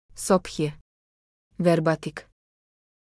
Sophie — Female Serbian AI voice
Sophie is a female AI voice for Serbian (Latin, Serbia).
Voice sample
Listen to Sophie's female Serbian voice.
Female